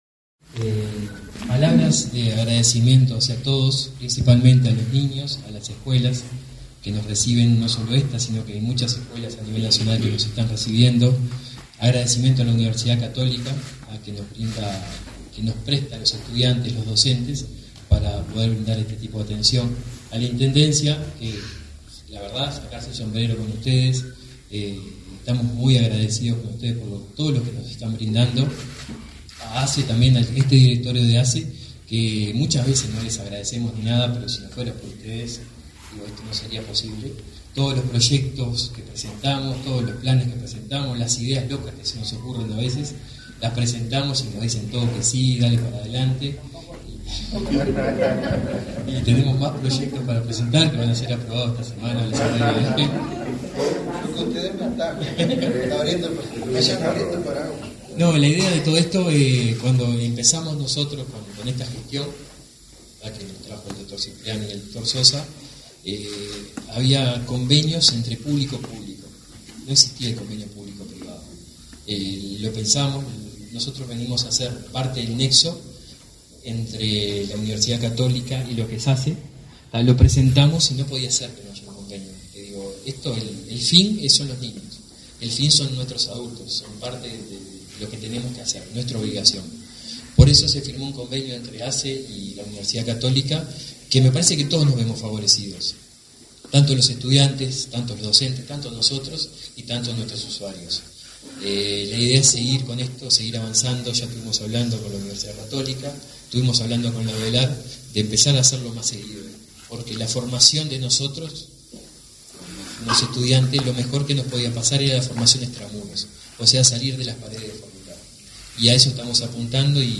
Conferencia de prensa del Plan Nacional de Salud Bucal en Florida
Conferencia de prensa del Plan Nacional de Salud Bucal en Florida 07/06/2022 Compartir Facebook X Copiar enlace WhatsApp LinkedIn El Plan Nacional de Salud Bucal, implementado por la Administración de los Servicios de Salud del Estado (ASSE), realizó una intervención en la escuela n.° 33 de la ciudad de Florida, este 7 de junio. En el acto participaron, el presidente de ASSE, Leonardo Cipriani, y el director del Plan de Salud Bucal, Néstor Graña.